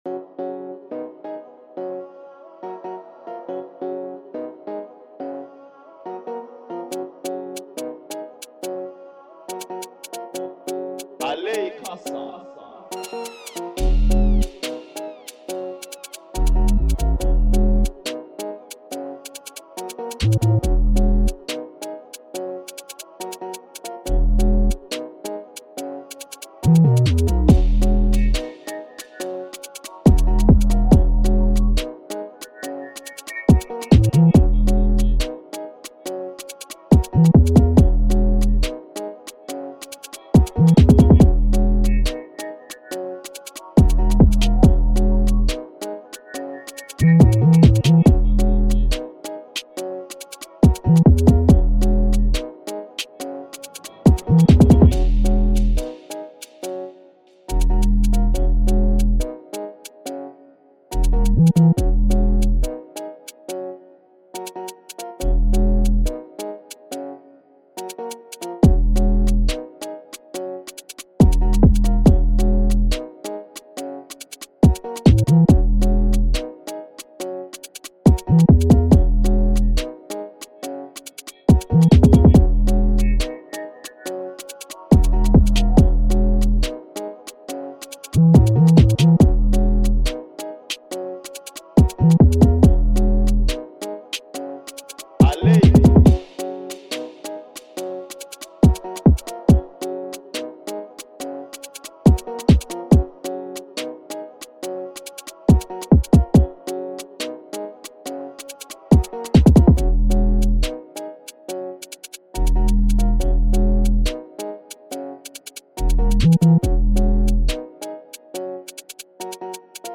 2022-01-12 1 Instrumentals 0
free beat